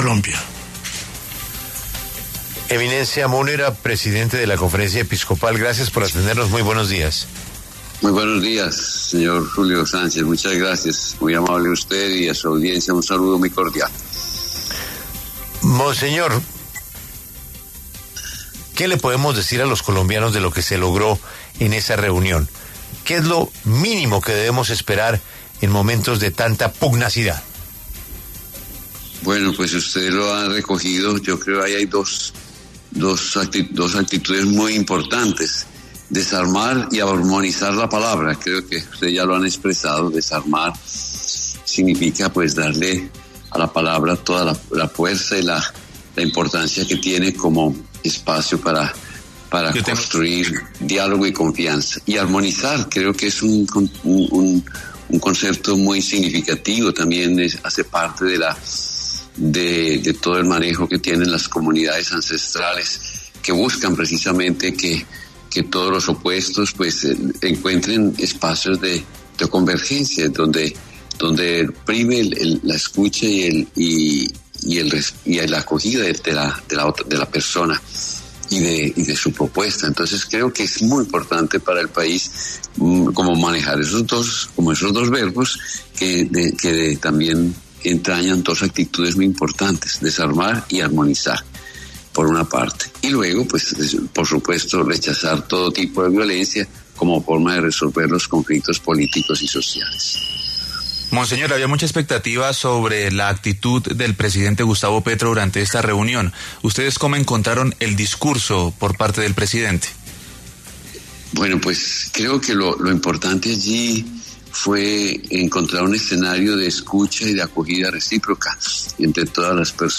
En entrevista con La W, el presidente de la Conferencia Episcopal, monseñor Francisco Javier Múnera, se refirió a la reunión que fue propiciada por la Iglesia católica, a la cual asistieron el presidente Gustavo Petro y otras autoridades como los presidentes de las Altas Cortes y el presidente del Senado, Efraín Cepeda.